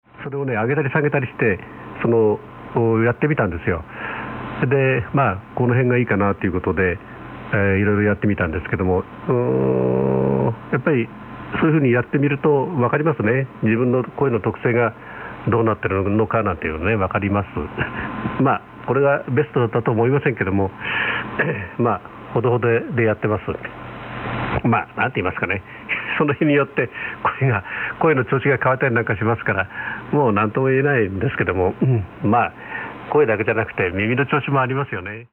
Sample Hi‑Fi SSB Audio
Rx:FT DX 9000D / Tx: TS-2000, Rx band width 4kHz